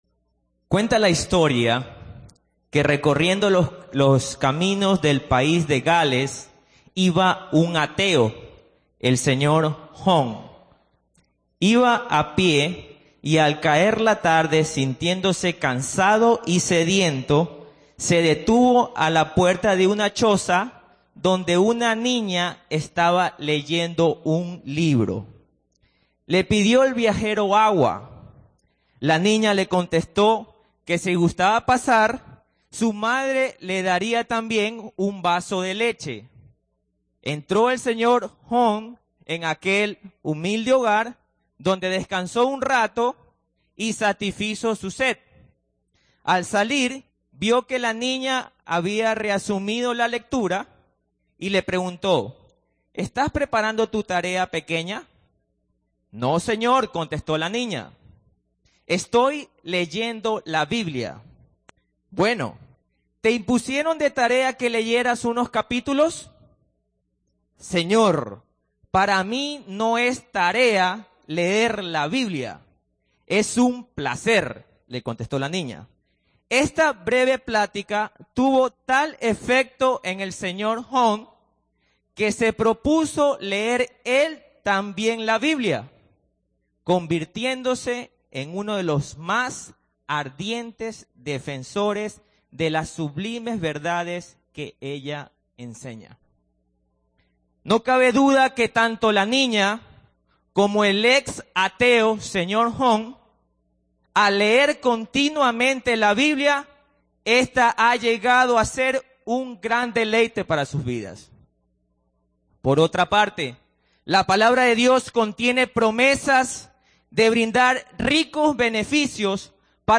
Mensaje predicado en la IGLESIA CRISTIANA TORRE FUERTE 28 de Septiembre del 2008
Audio del sermón